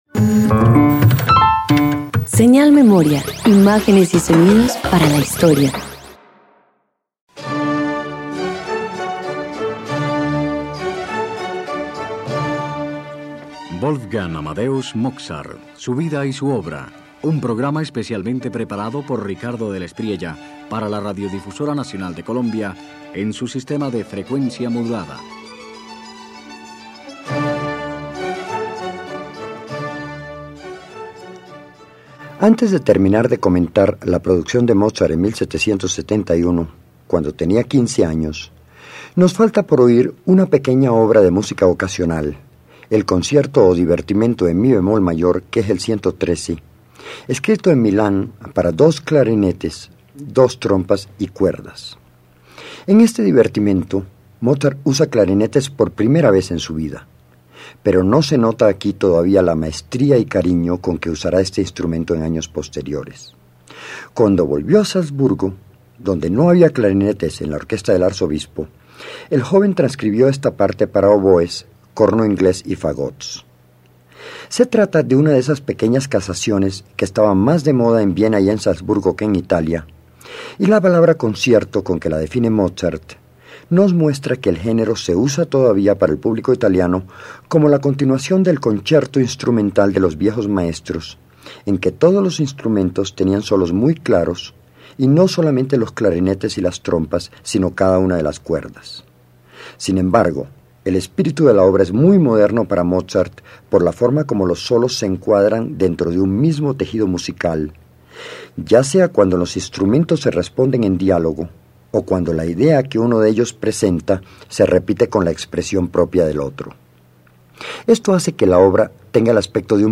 Esta obra es un pequeño laboratorio de equilibrio, cada instrumento dialoga con otro, sin protagonista.
Radio colombiana